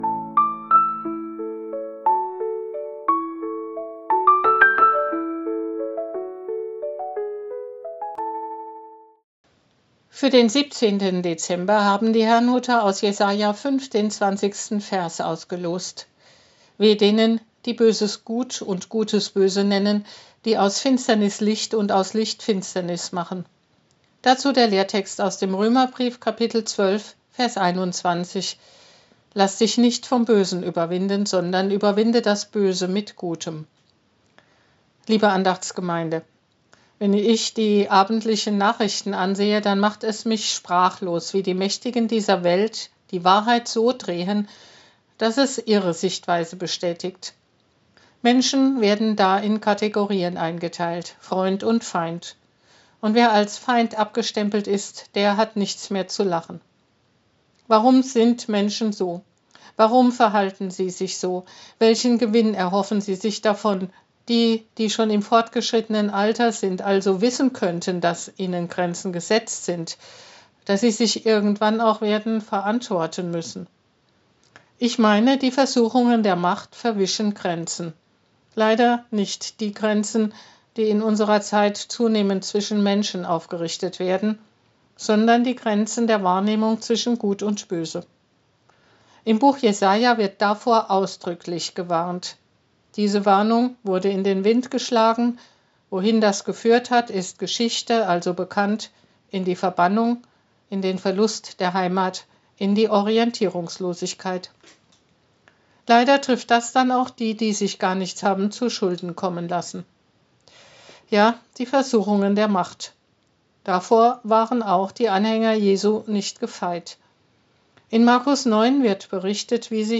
Losungsandacht für Mittwoch, 17.12.2025 – Prot.